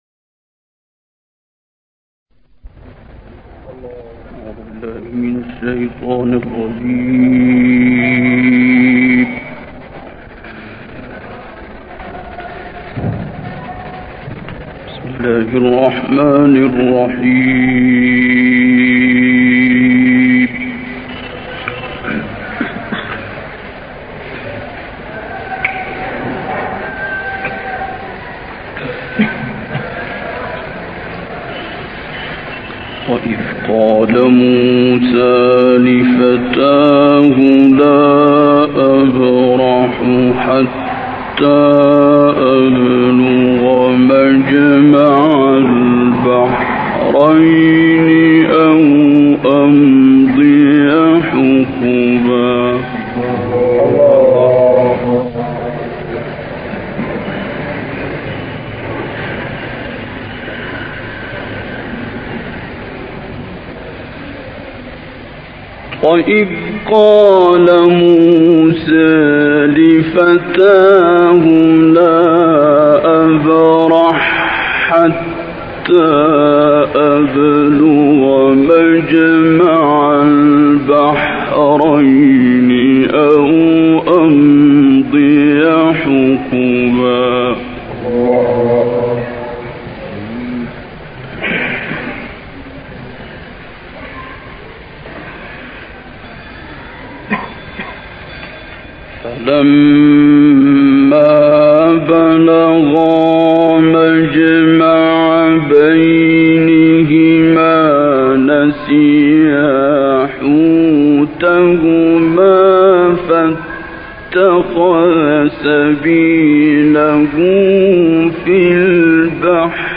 این تلاوت در مسجد جامع یلبغا دمشق اجرا شده است و مدت زمان آن 43 دقیقه و 58 ثانیه است.